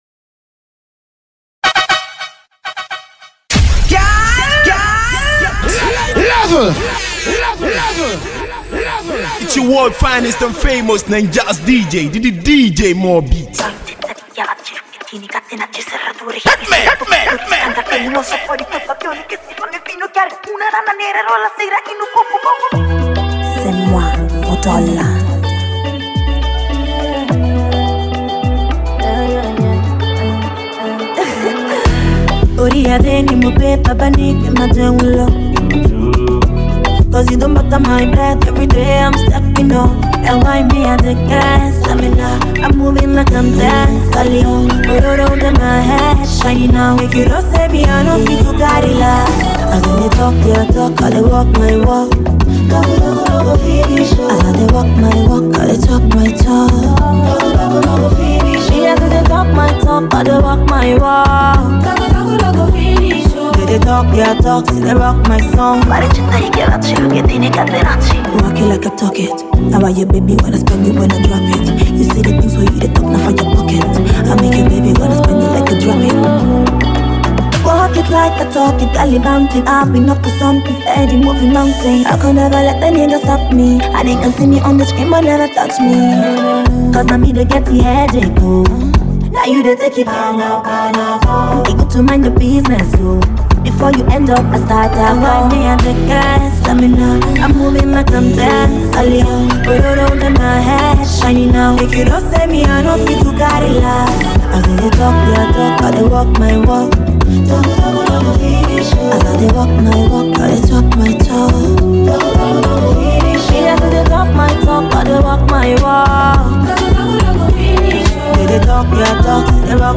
DJ mix